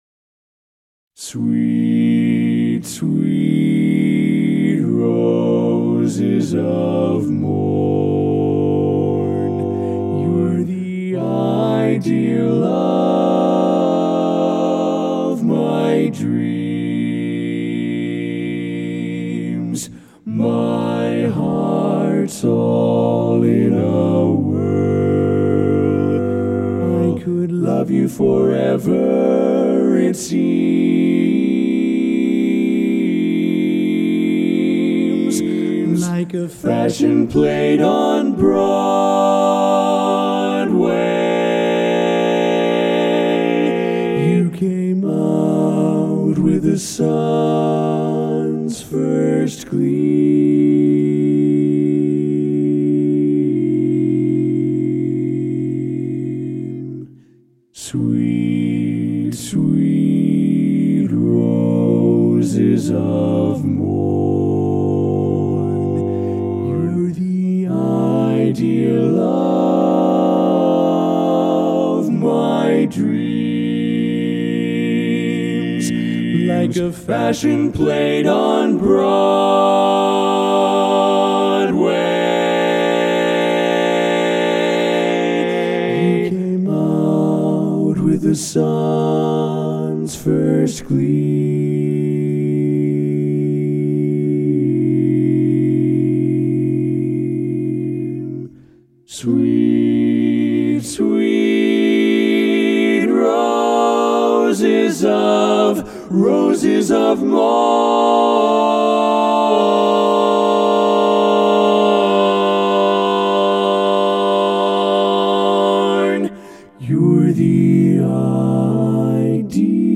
Barbershop
Full Mix